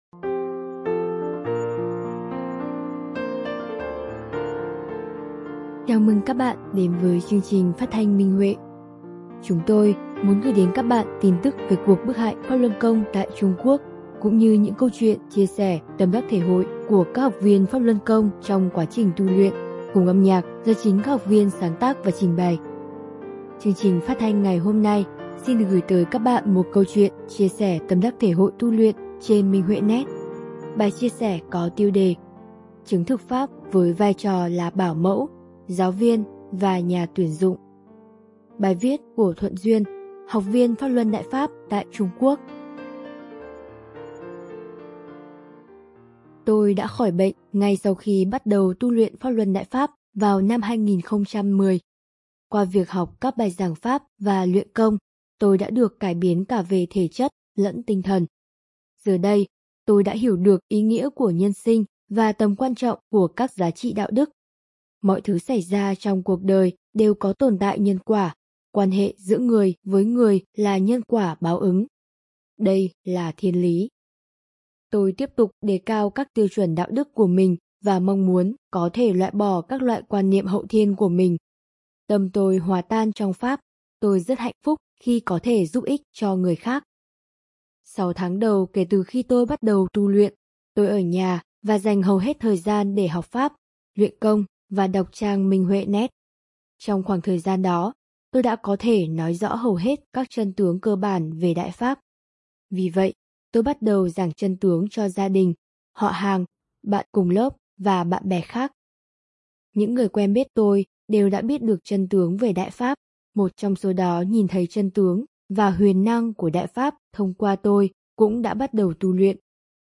Chúng tôi muốn gửi đến các bạn tin tức về cuộc bức hại PhápLuân Côngtại Trung Quốc cũng như những câu chuyện chia sẻ tâm đắc thể hội của các học viên trong quá trình tu luyện, cùng âm nhạc do chính các học viên sáng tác và trình bày.